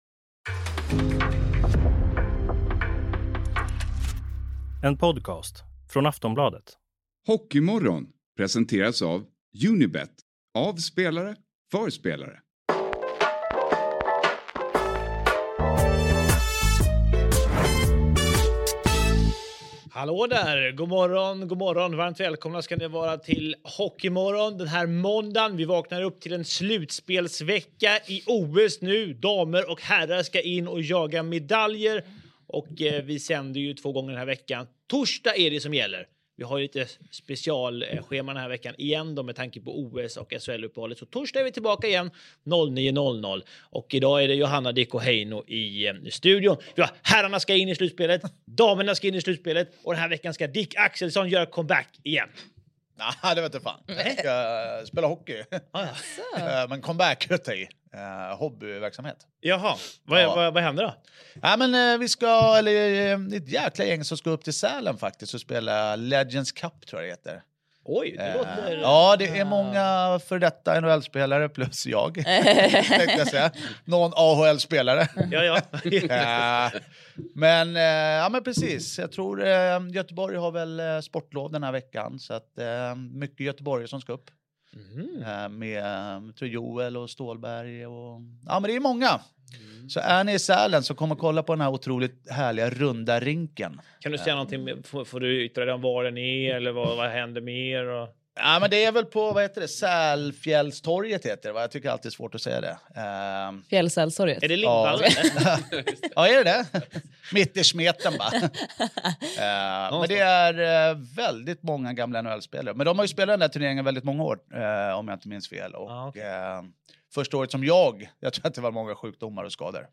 Hockeymorgon Attack mot Hallam: ”Matchat laget fel” Play episode February 16 1h 3m Bookmarks Episode Description Tre Kronor slutar trea i gruppen och får en längre väg till medalj. Panelen har många funderingar kring Sam Hallams agerande i media, Lucas Raymonds utvisning och målvaktssituationen.